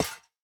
Monster Spawner step2 JE1 BE1.wav
Monster_Spawner_step2_JE1_BE1.wav